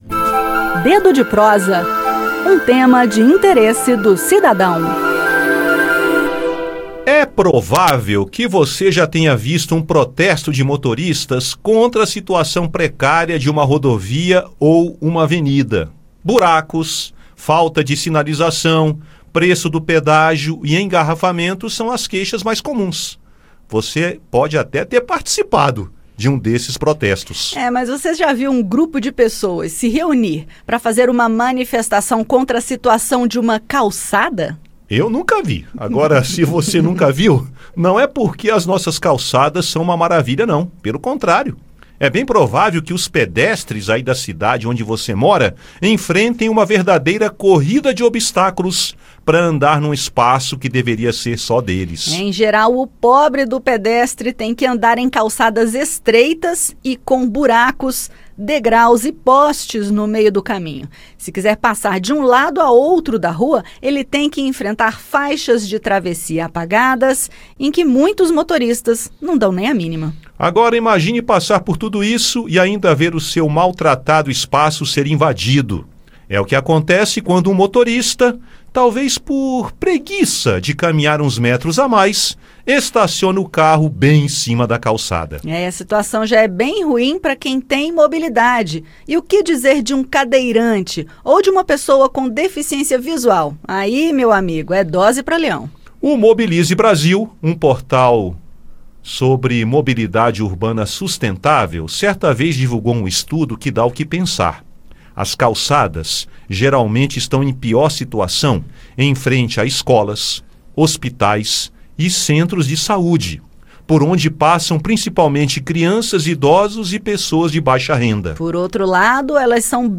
Calçadas em boas condições são fundamentais para a mobilidade urbana e a segurança de pedestres, cadeirantes e pessoas com deficiência visual.  No bate-papo, conheça o estudo divulgado pelo Mobilize Brasil, um portal sobre mobilidade urbana sustentável que promove ações pelo planejamento e conservação de vias.